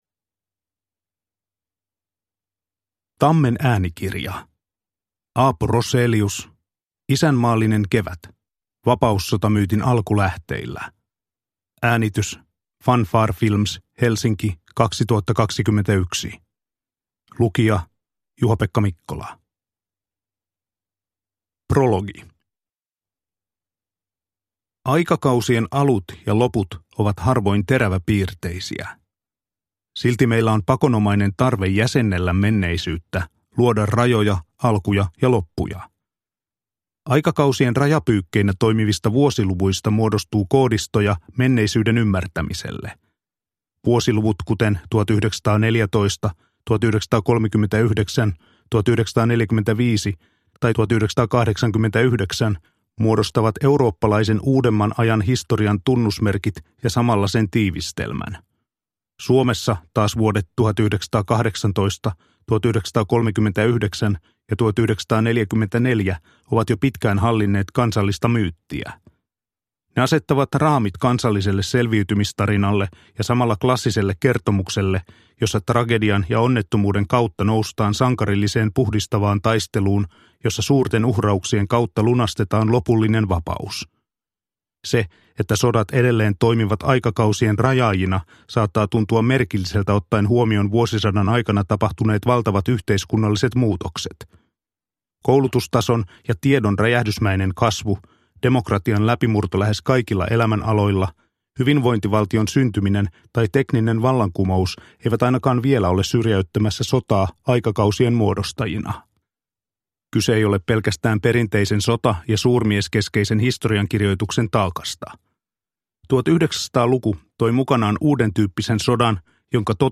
Isänmaallinen kevät – Ljudbok – Laddas ner